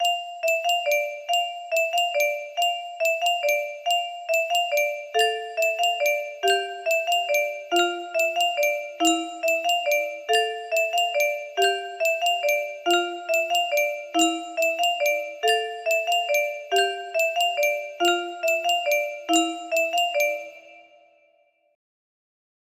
Carol of the Bells music box melody
Wow! It seems like this melody can be played offline on a 15 note paper strip music box!